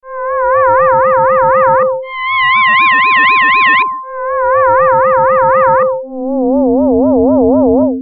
5.脱力する様な音色